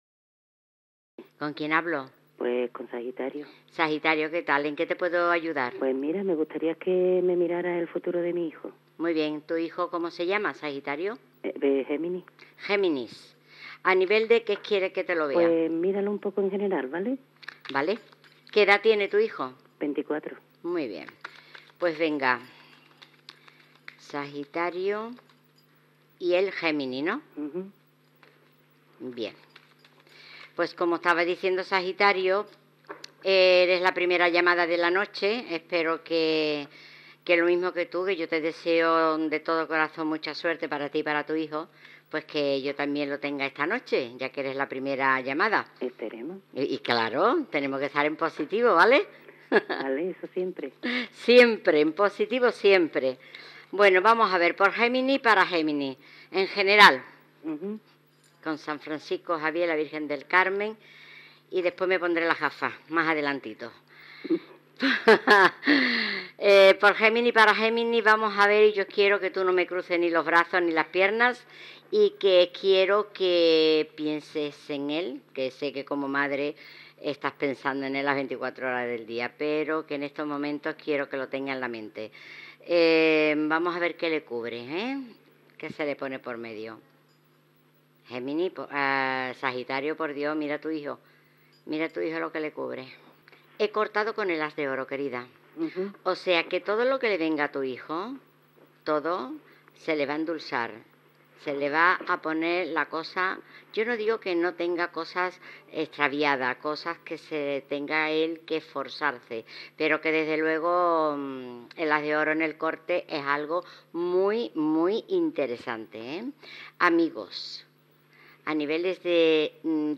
Lectura de les cartes del tarot a una oïdora que pregunta pel futur del seu fill
FM